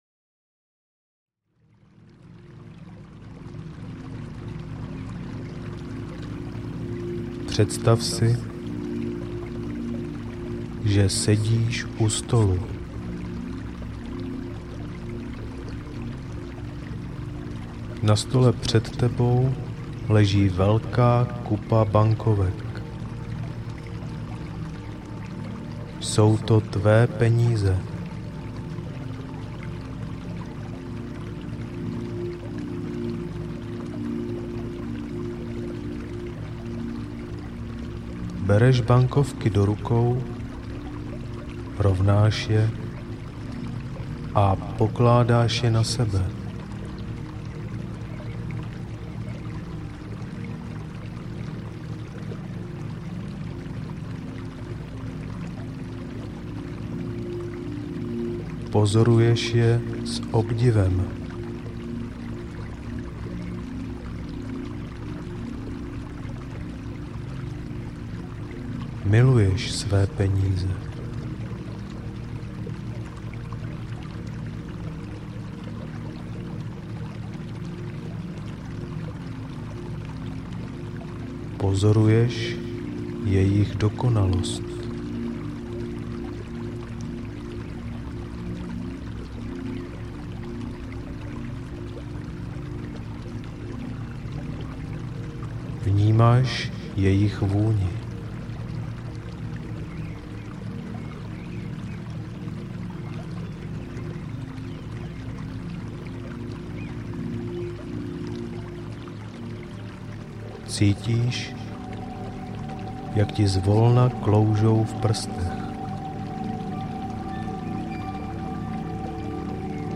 Finanční hojnost audiokniha
Ukázka z knihy